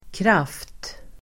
Uttal: [kraf:t]